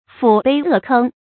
拊背扼吭 fǔ bèi è kēng
拊背扼吭发音